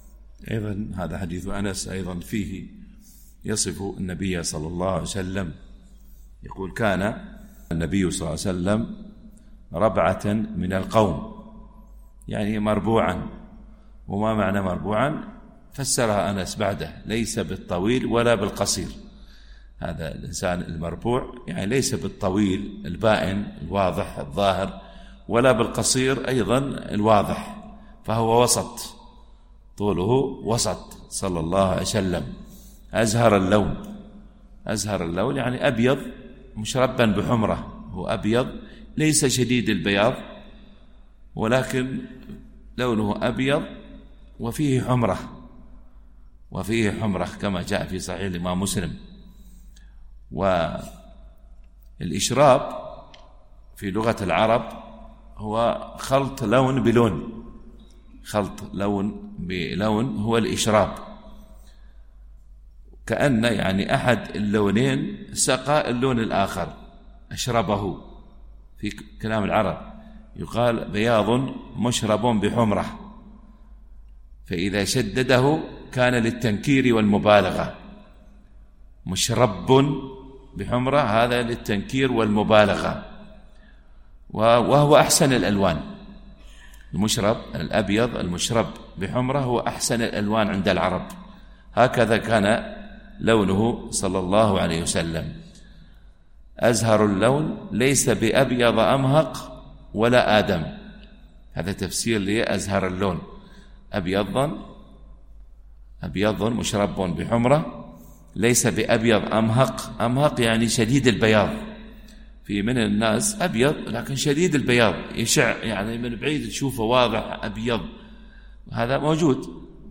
التنسيق: MP3 Mono 44kHz 194Kbps (VBR)